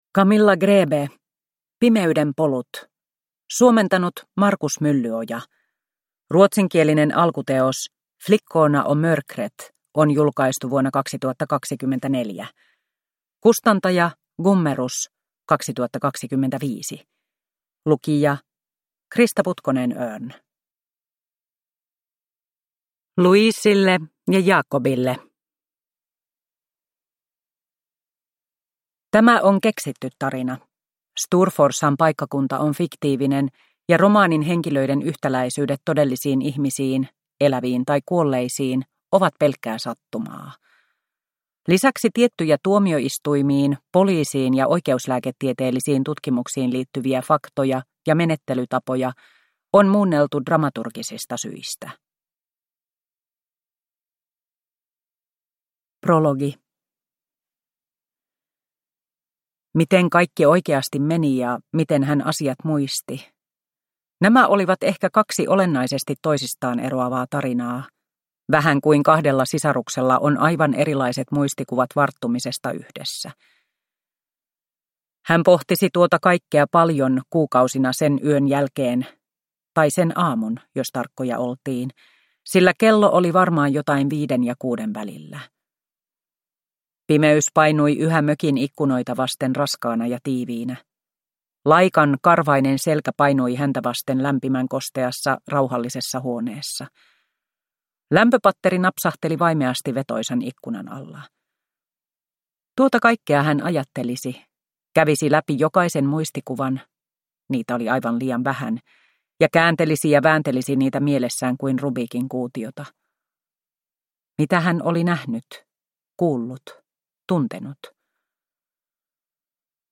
Pimeyden polut – Ljudbok